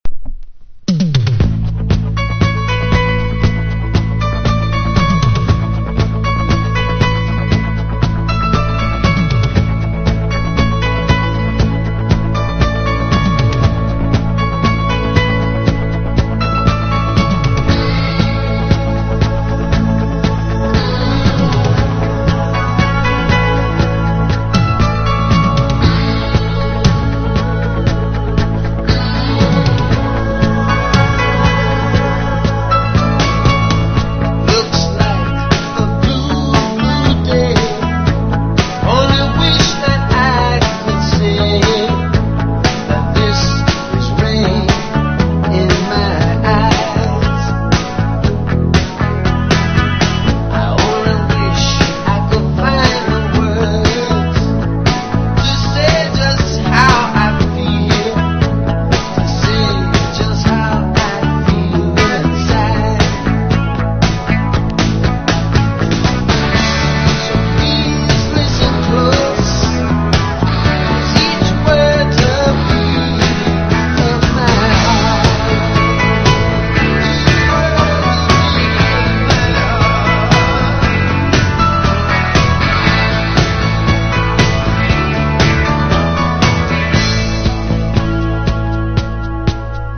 ソウル・フィーリング溢れるフェイク・ラテン～サルサ盤！
その他R&Bテイストの楽曲も◎！